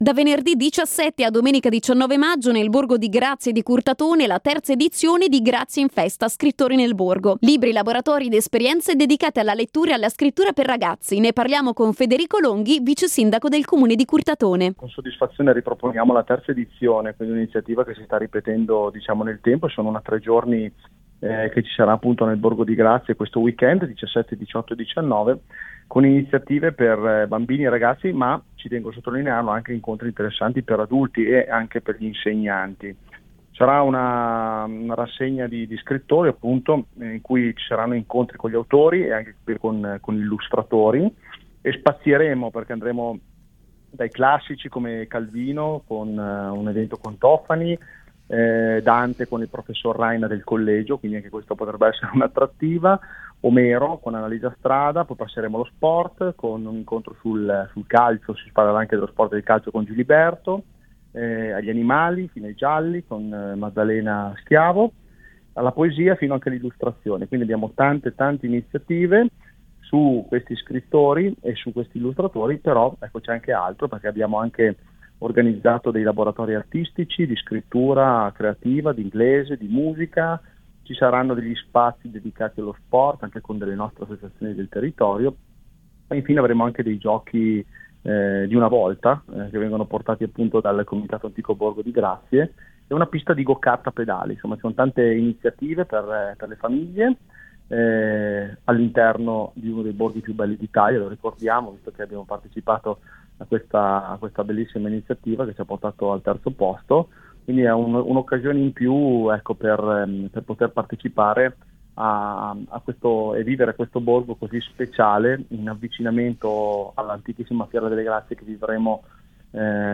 Ai nostri microfoni il vice sindaco di Curtatone Federico Longhi: